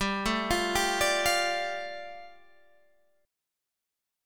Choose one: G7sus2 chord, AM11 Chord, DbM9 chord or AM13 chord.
G7sus2 chord